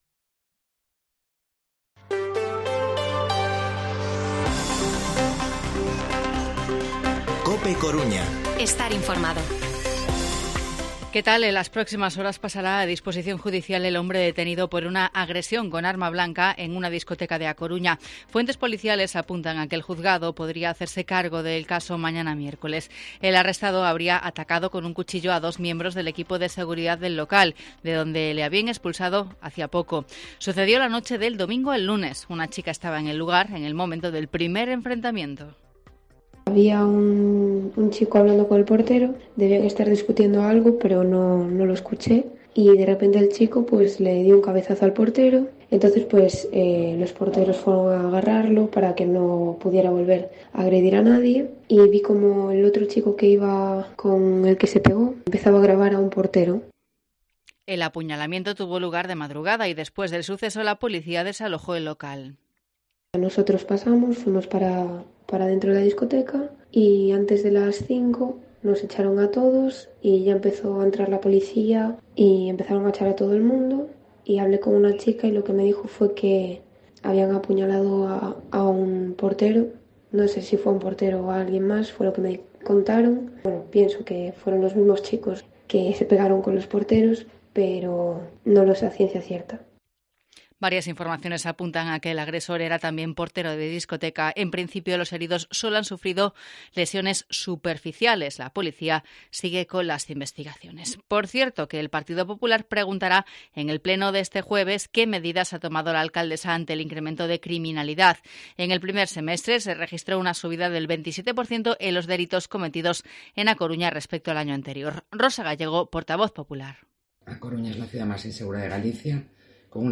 Informativo Mediodía COPE Coruña martes, 6 de septiembre de 2022 14:20-14:30